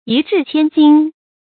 一掷千金 yī zhì qiān jīn
一掷千金发音
成语正音 掷；不能写作“zhènɡ”。